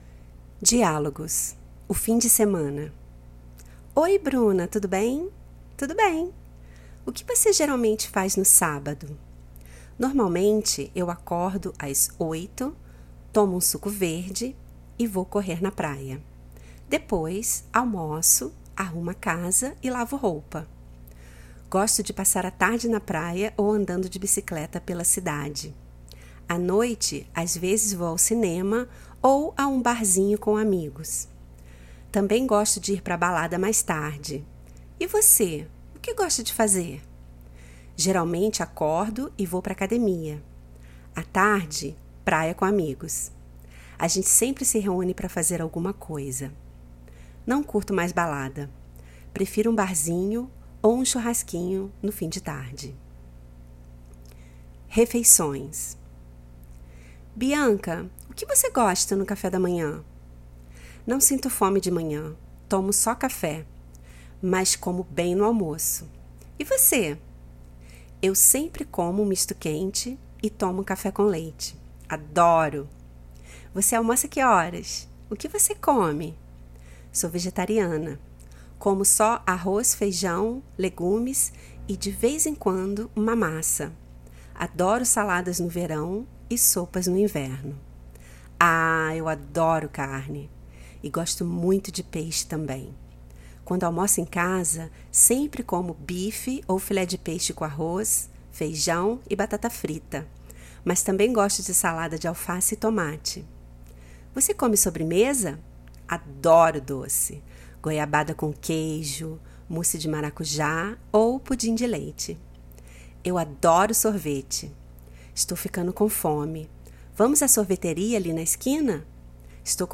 Dialogues - (weekend / meals) - Tudo Bem? Brazilian Portuguese
Dialogos-Weekend.mp3